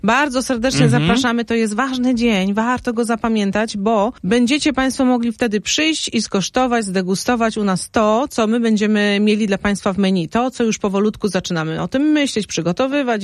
Jak powinien wyglądać świąteczny stół, mówi w naszym studiu